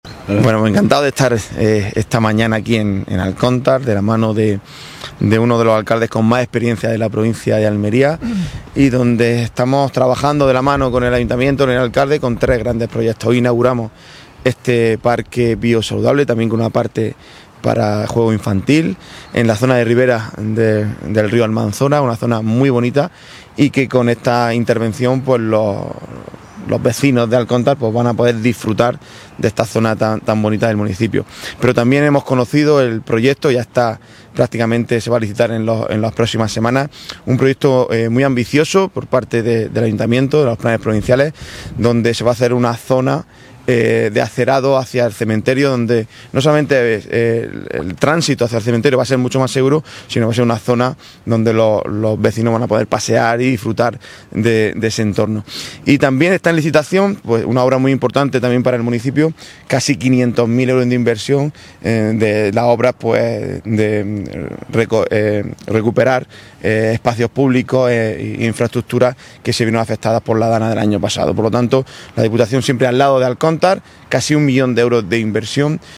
AUDIO-PRESIDENTE-INAUGURACION-PARQUE-BIOSALUDABLE.mp3